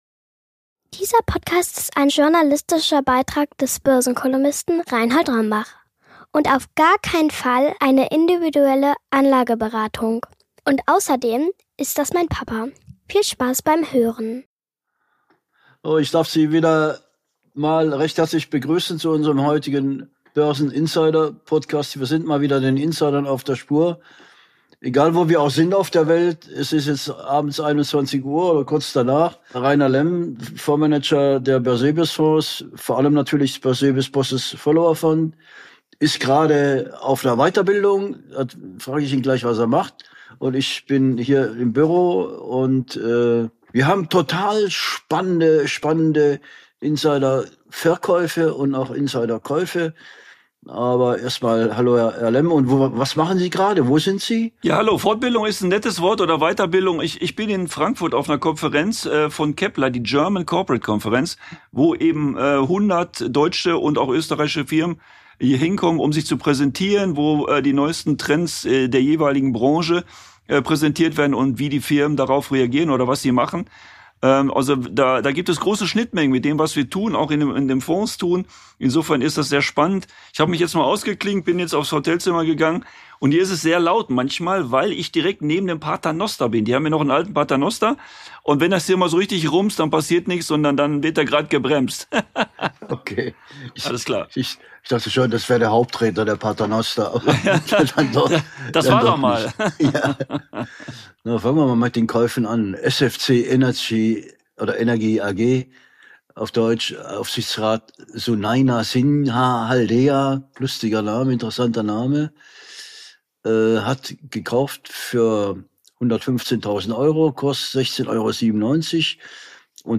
#18 Börseninsider live aus Frankfurt: Wasserstoff, Rüstung und die Top-Aktien von morgen